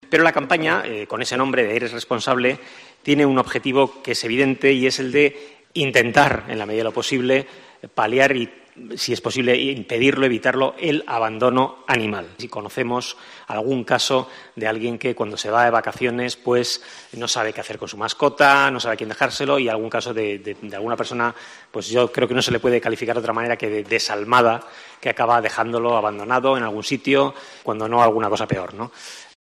Enrique Ramos, edil de Ecología